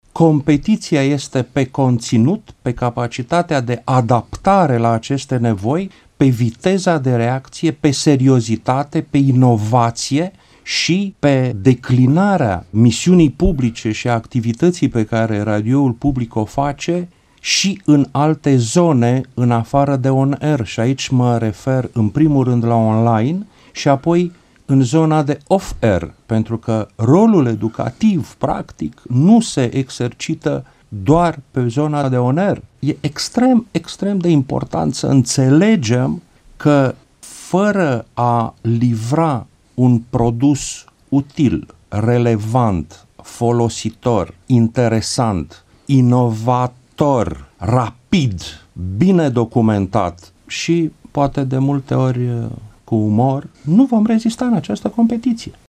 Preşedintele director general al Radiodifuziunii Române, Ovidiu Miculescu: